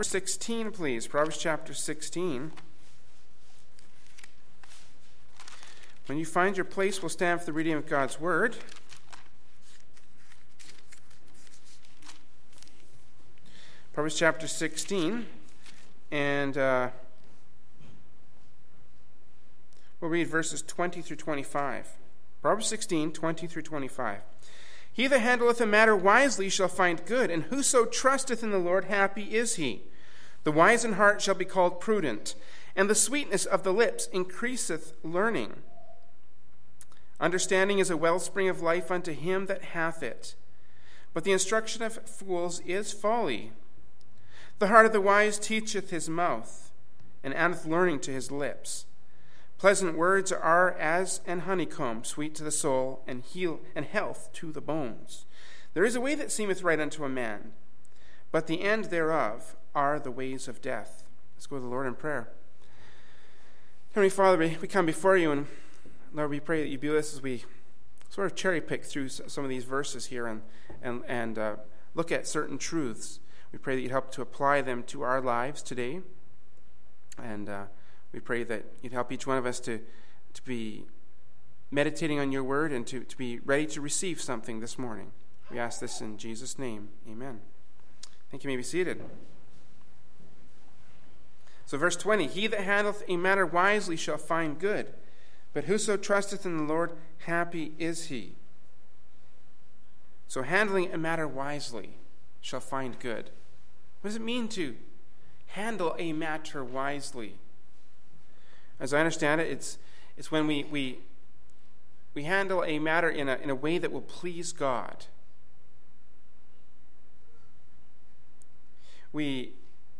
Kamloops, B.C. Canada
Adult Sunday School